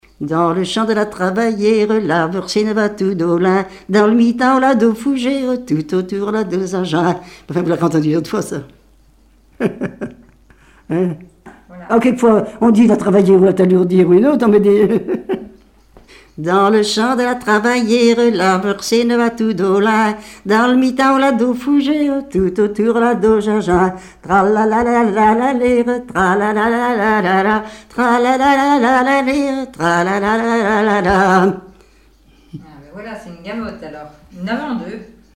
Mémoires et Patrimoines vivants - RaddO est une base de données d'archives iconographiques et sonores.
Couplets à danser
danse : branle : avant-deux
Pièce musicale inédite